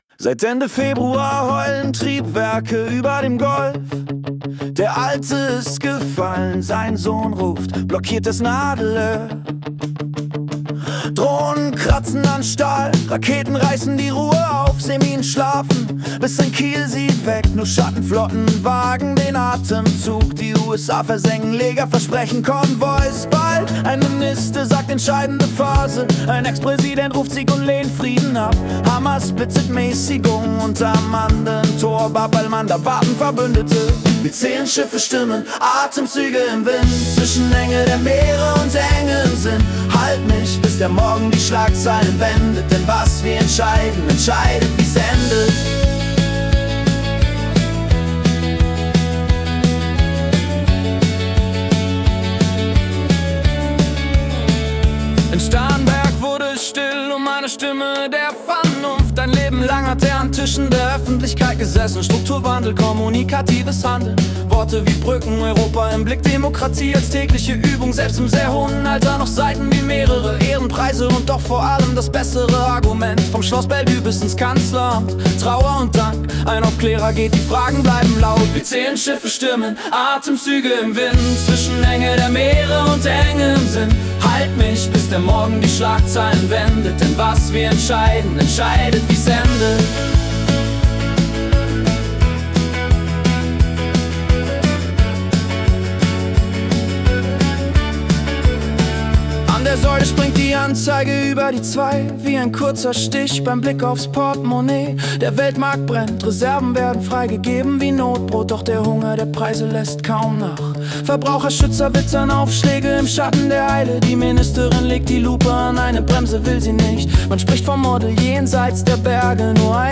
Die Nachrichten vom 15. März 2026 als Singer-Songwriter-Song interpretiert.